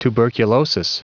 Prononciation du mot tuberculosis en anglais (fichier audio)
Prononciation du mot : tuberculosis